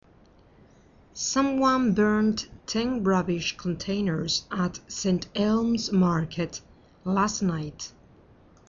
Escucha a la Redactora Jefe y completa las noticias con las siguientes palabras: